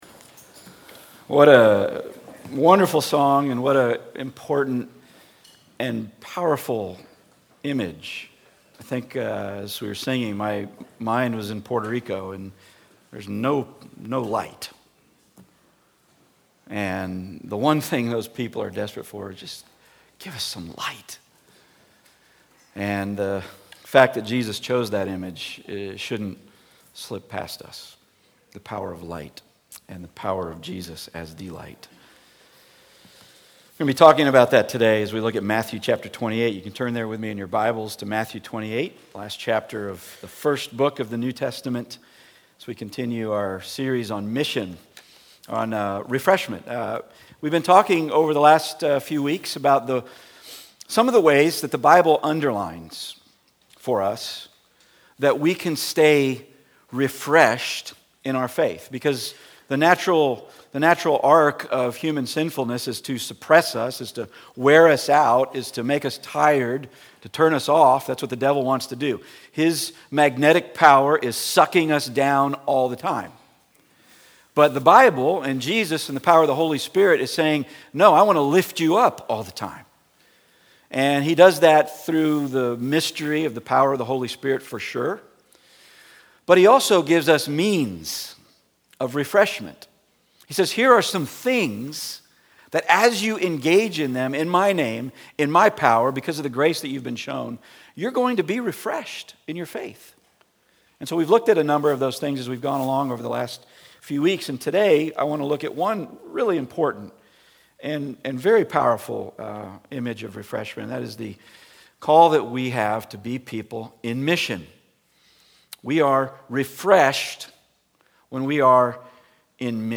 Passage: Matthew 28:16-20 Service Type: Weekly Sunday